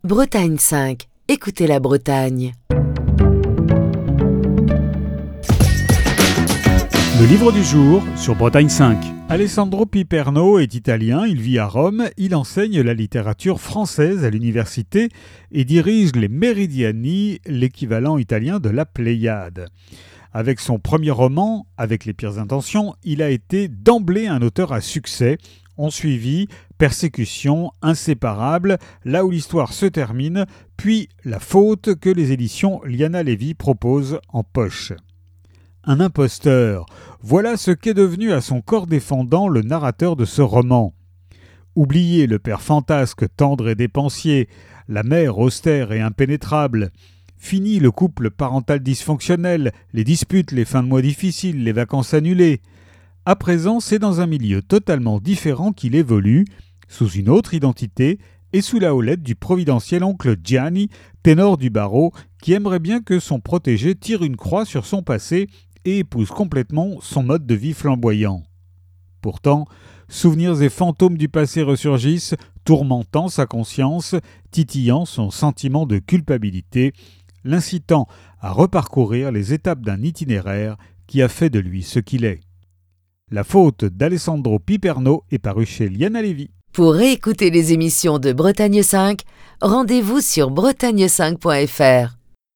Chronique du 17 avril 2024.